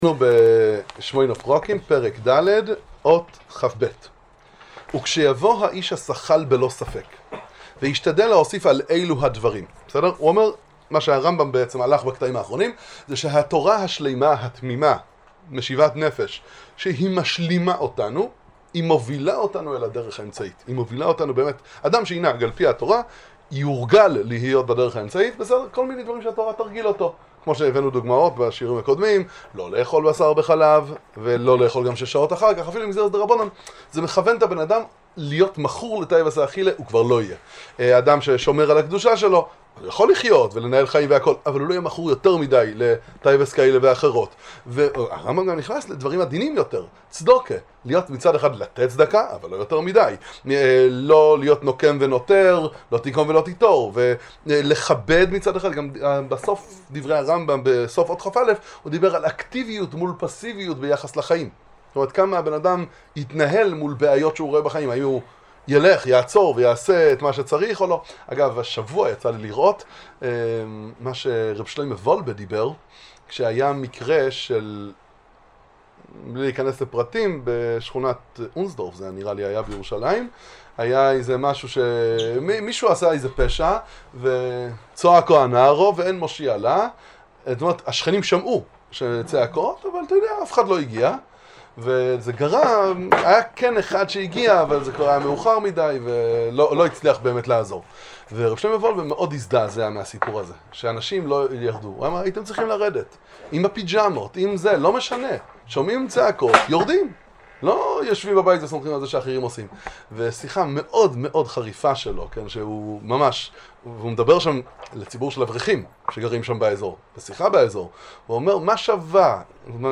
שיעור בהקדמת הרמב"ם לפרקי אבות